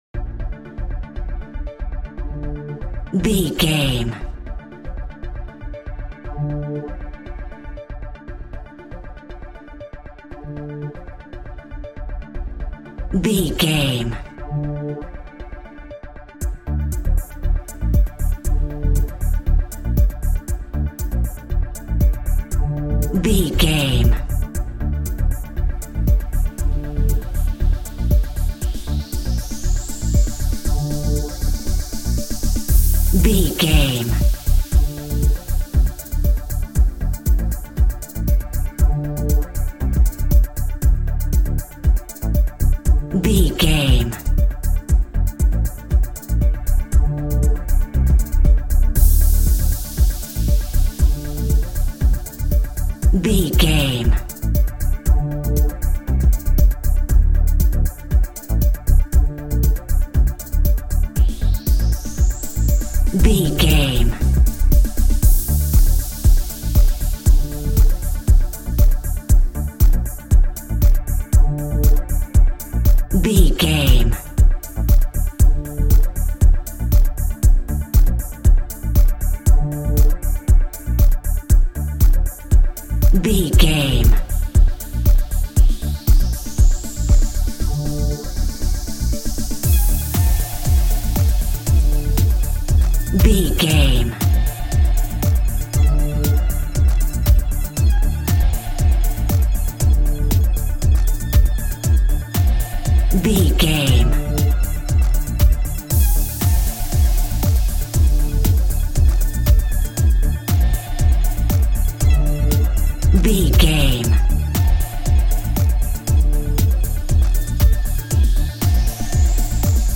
Pop Chart Electronic Dance Music Alt.
Fast paced
In-crescendo
Aeolian/Minor
groovy
uplifting
energetic
bouncy
synthesiser
drum machine
house
electro dance
synth bass
upbeat